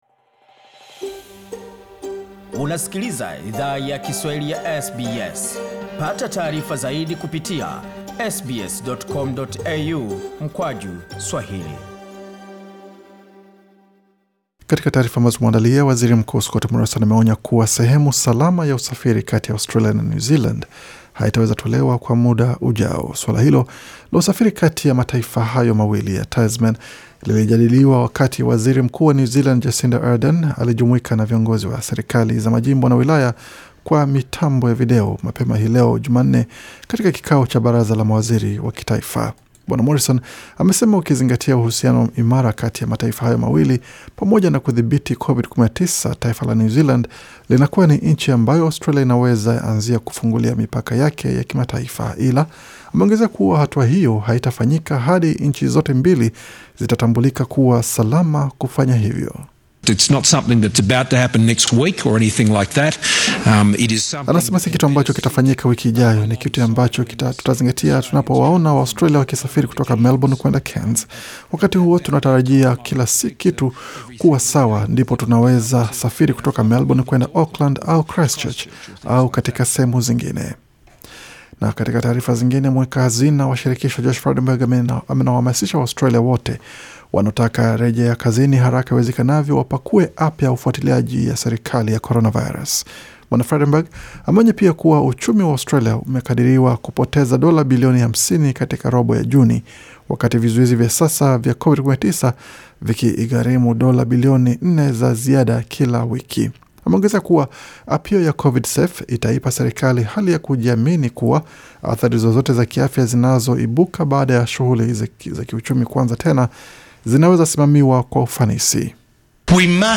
Taarifa za habari: Safari zakimataifa bado zipo mashakani waziri mkuu asema